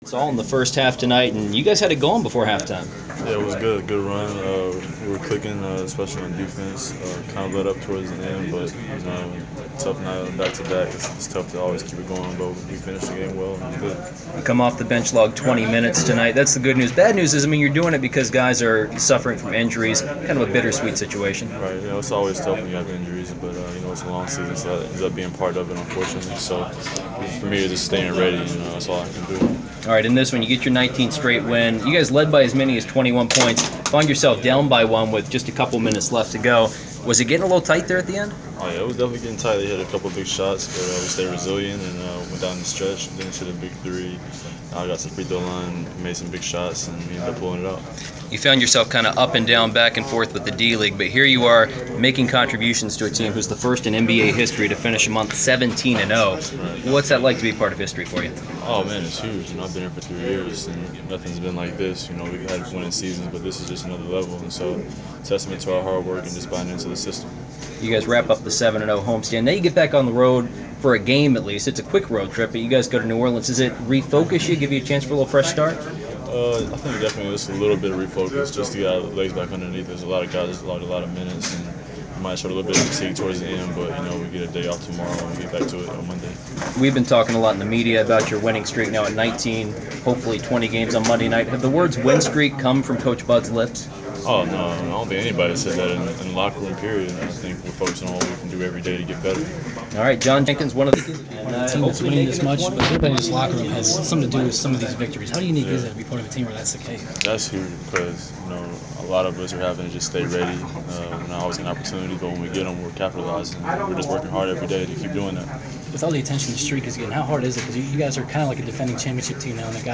Inside the Inquirer: Postgame interview with Atlanta Hawk John Jenkins (1/31/15)
We attended the postgame presser of Atlanta Hawks’ guard John Jenkins following his team’s 91-85 win over the Philadelphia 76ers on Jan. 31.